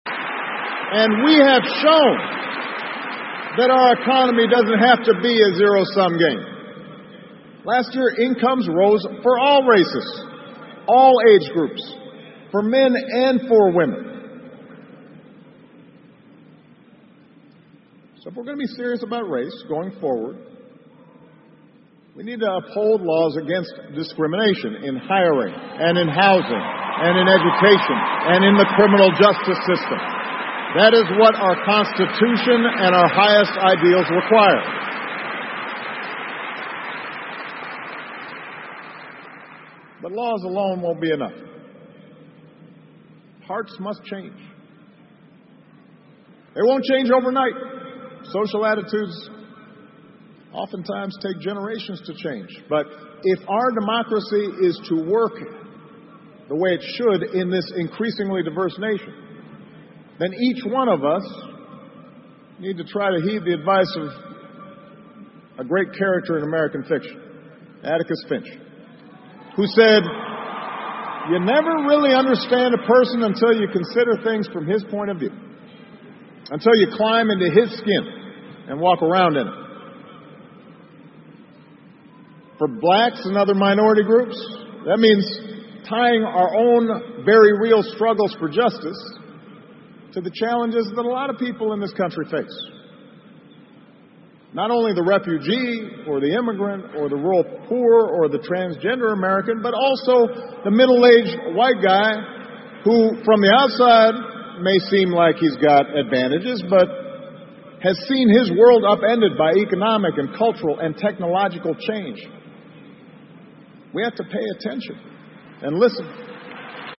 奥巴马每周电视讲话：美国总统奥巴马告别演讲(9) 听力文件下载—在线英语听力室